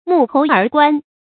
成語注音 ㄇㄨˋ ㄏㄡˊ ㄦˊ ㄍㄨㄢˋ
成語拼音 mù hóu ér guàn
發音讀音
沐猴而冠發音
成語正音 冠，不能讀作“ɡuān”。